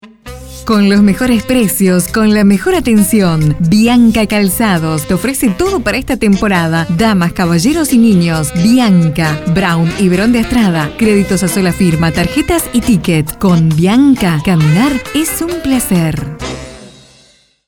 Junge dynamische Sprecherin mit Erfahrung in Werbung für Radio und TV
kastilisch
Sprechprobe: eLearning (Muttersprache):
female voice over artist spanish.